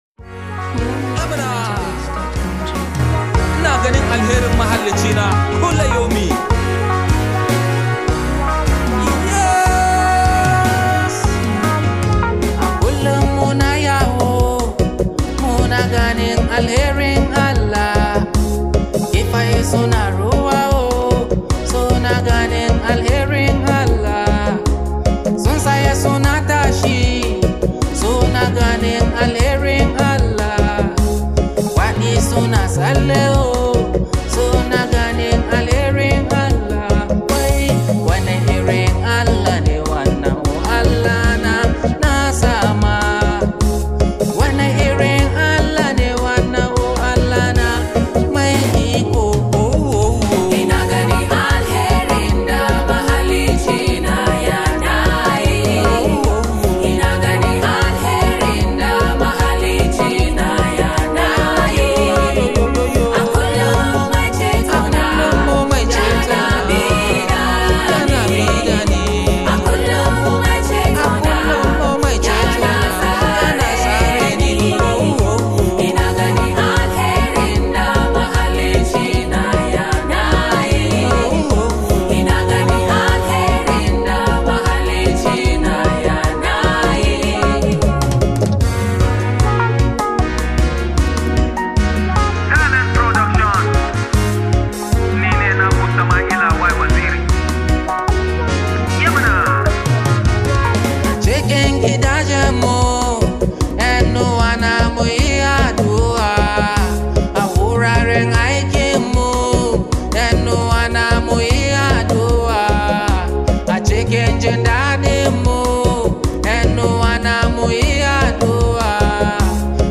soulful Hausa song
calm melodies and emotional lyrics